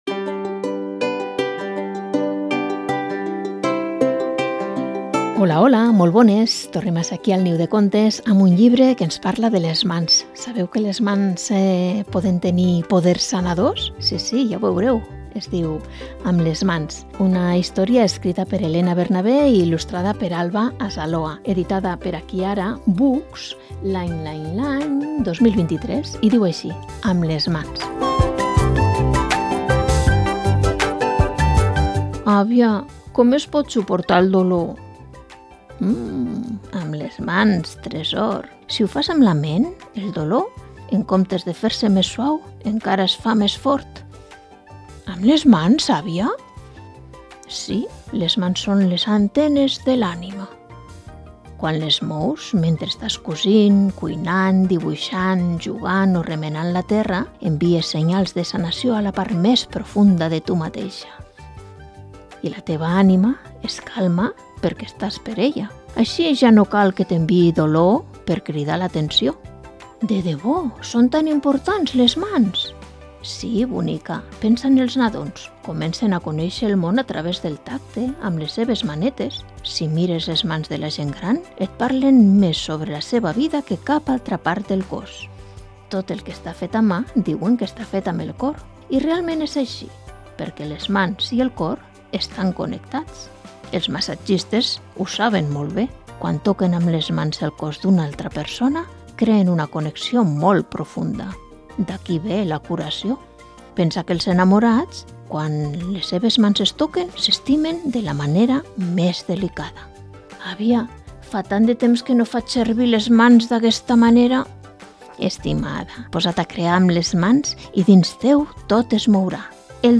Programa de lectura de contes